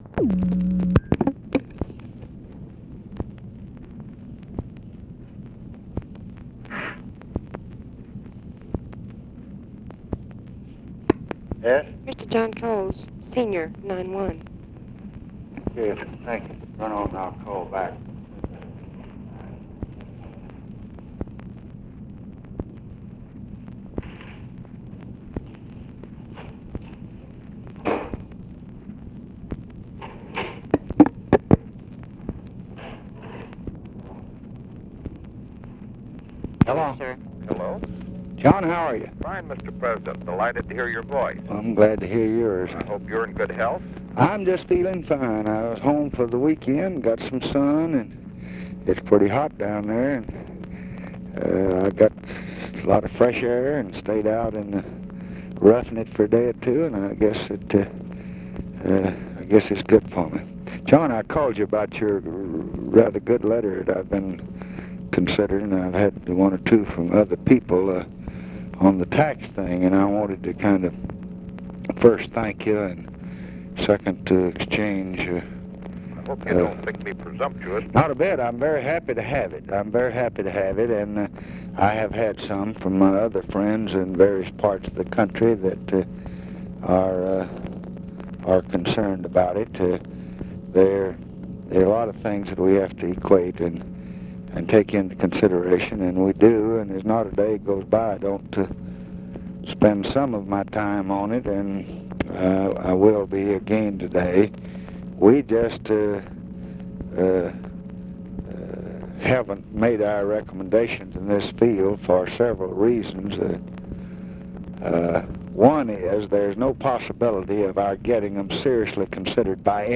Conversation with JOHN COWLES, June 8, 1966
Secret White House Tapes